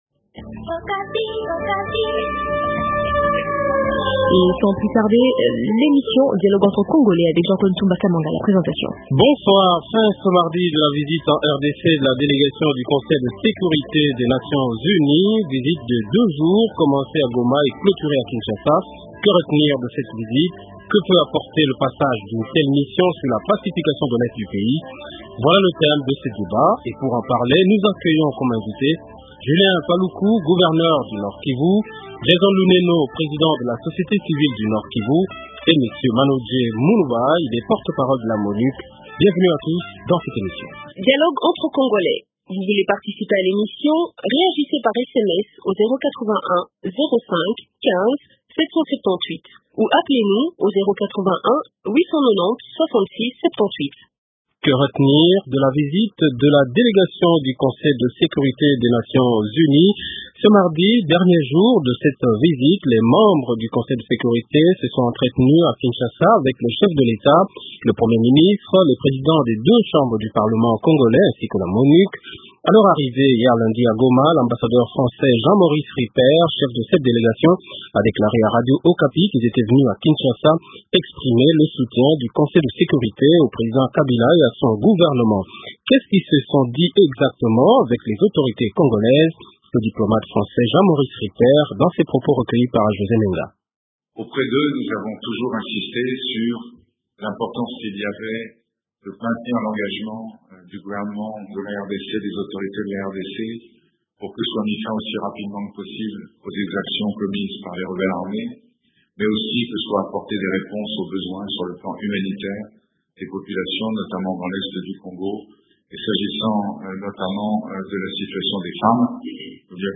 Invitésrn rn-Julien Paluku, gouverneur du Nord Kivu